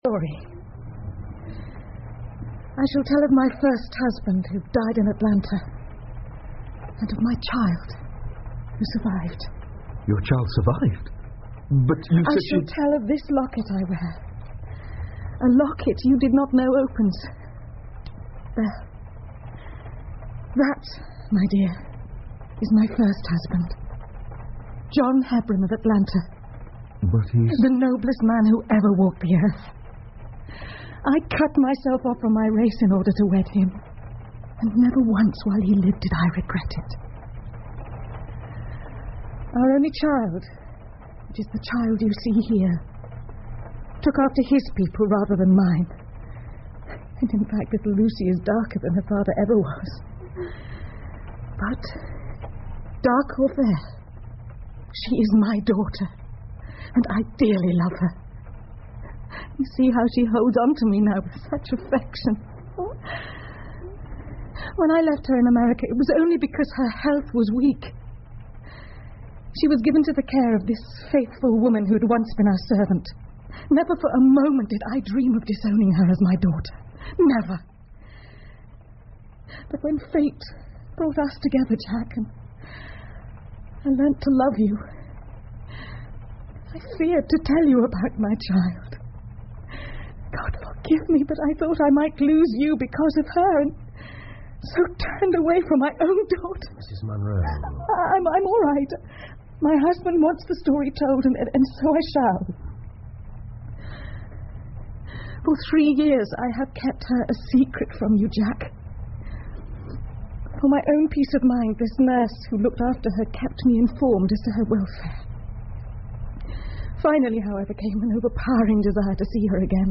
福尔摩斯广播剧 The Yellow Face 8 听力文件下载—在线英语听力室